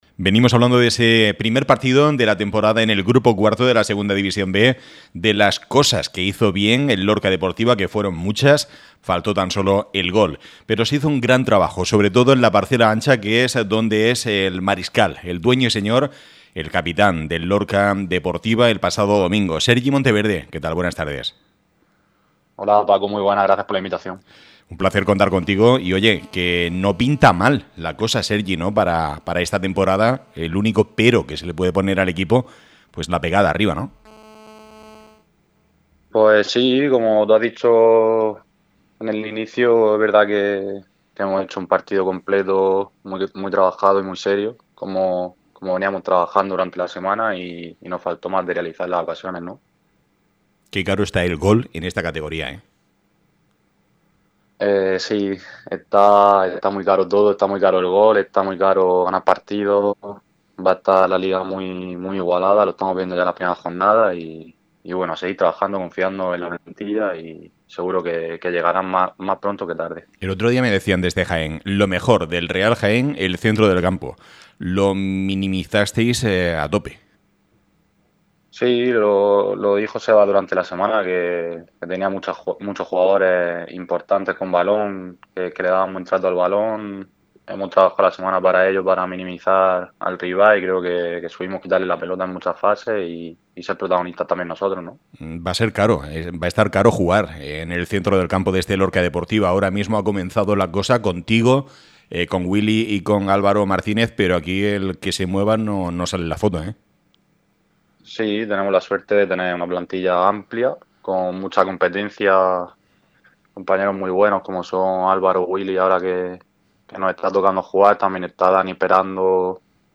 ÁREA LORCA RADIO. Deportes.